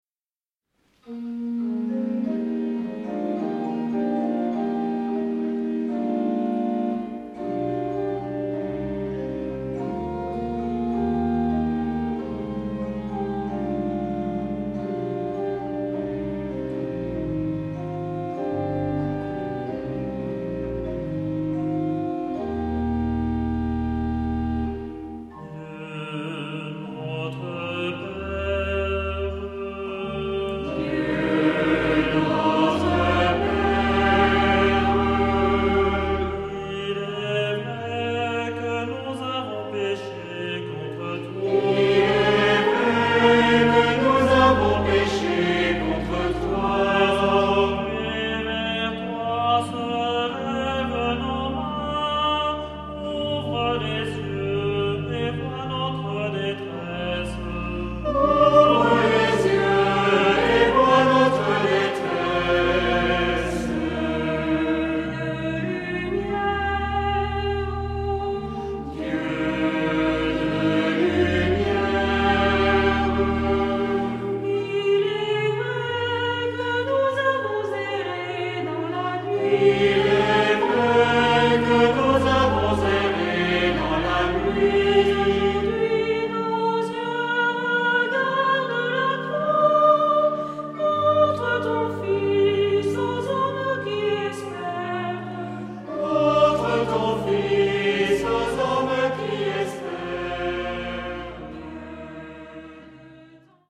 Genre-Style-Forme : Prière ; Invocation ; Canon
Caractère de la pièce : suppliant ; recueilli ; calme
Type de choeur : SA OU SB OU TB  (2 voix mixtes OU égales )
Instrumentation : Orgue  (1 partie(s) instrumentale(s))
Tonalité : mi bémol majeur